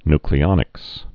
(nklē-ŏnĭks, ny-)